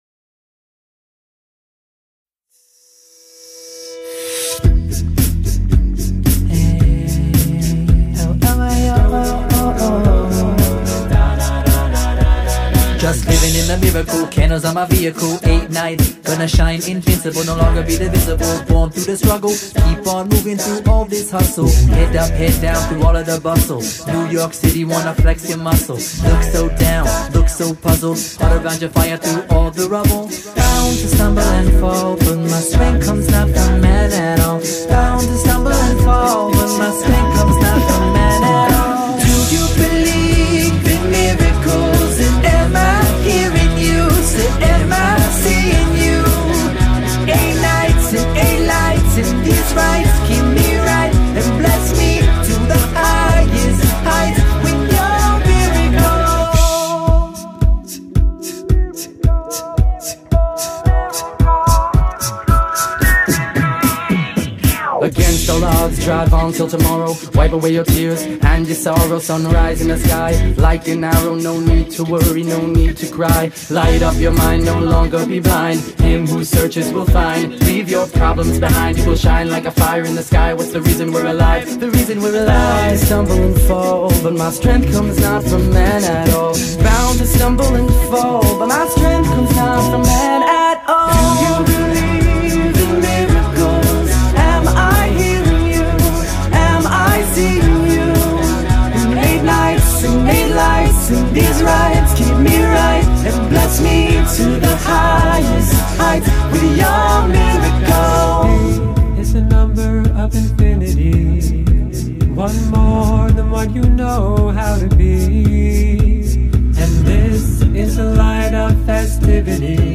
שירי אקפלה
כל הקולות מיוצרים ע" קולות מהפה.
זה עבודת צוות מטורפת הם בערך 10 גברים .